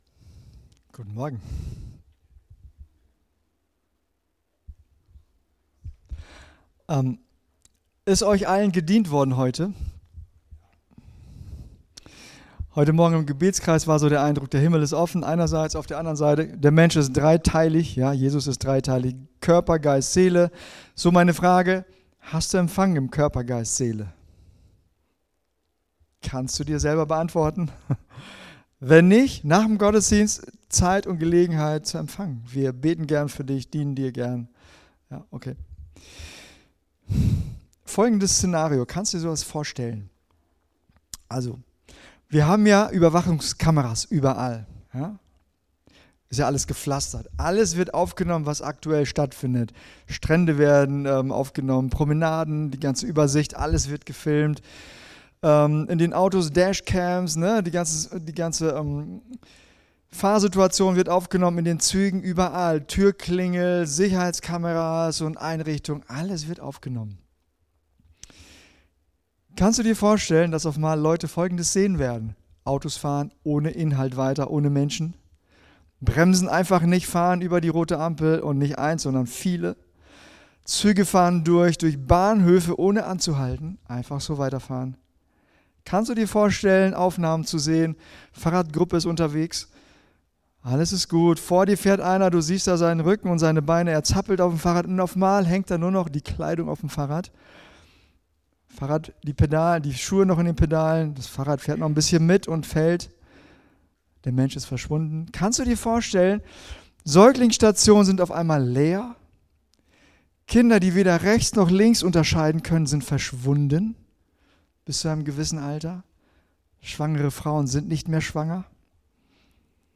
Gottesdienst | Oase Christengemeinde